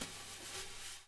SND DRUMAA-L.wav